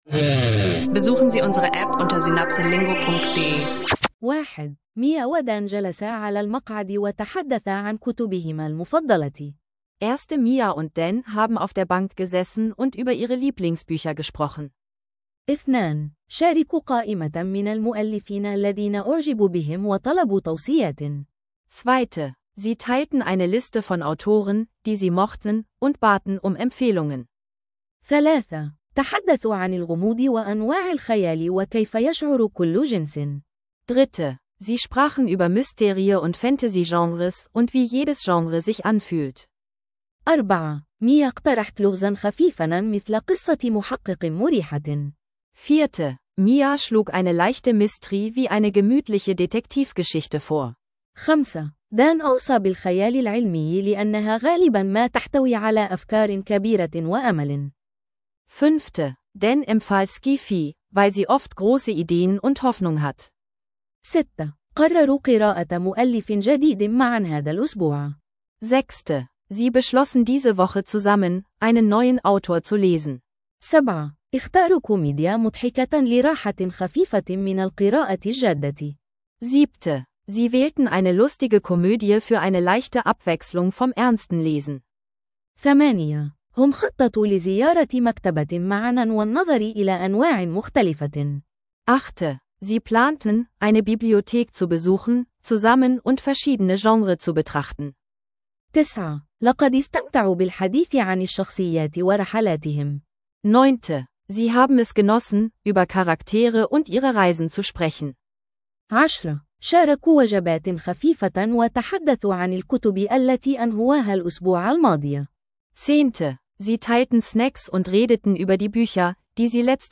In dieser Folge von Arabisch lernen Podcast erkunden wir Bücher, Lesen und globale Diplomatie. Freies Gespräch über Lieblingsbücher, Genres, Empfehlungen – kombiniert mit klaren Erklärungen zu internationalen Beziehungen, perfekt für Arabisch lernen leicht gemacht.